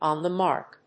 アクセントon the márk